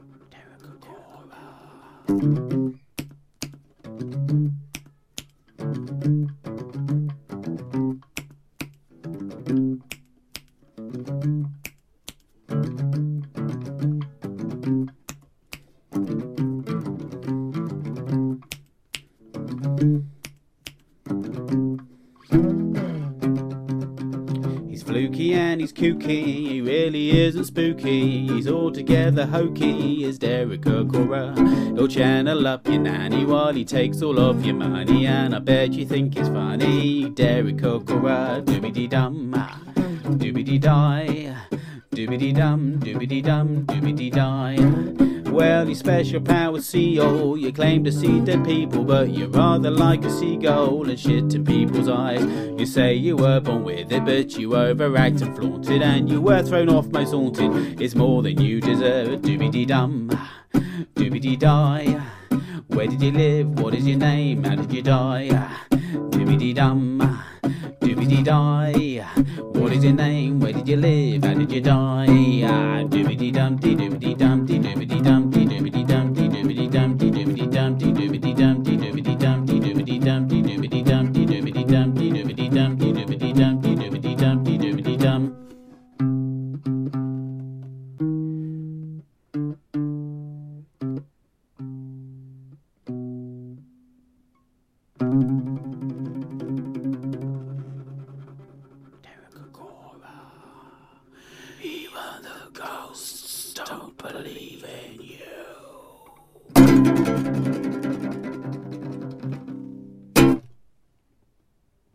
parody song thing. All about a fake medium.